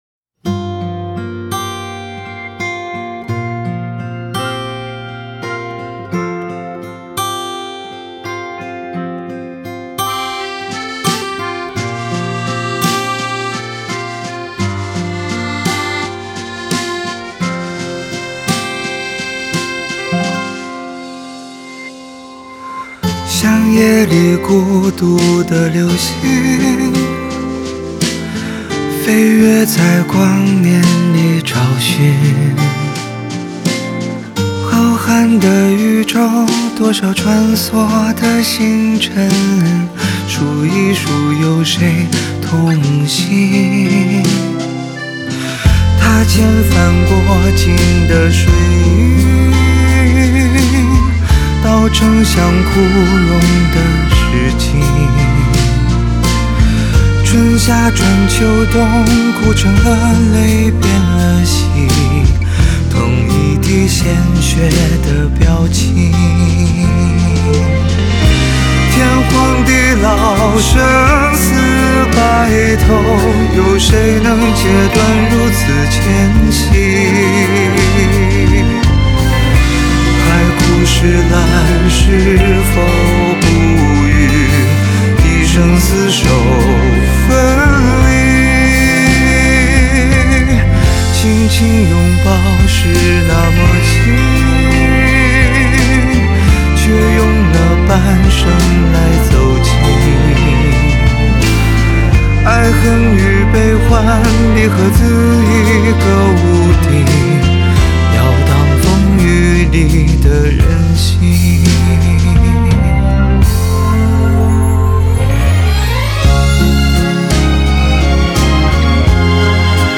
Ps：在线试听为压缩音质节选，体验无损音质请下载完整版
录音棚：Imagine Studio/北京唱片厂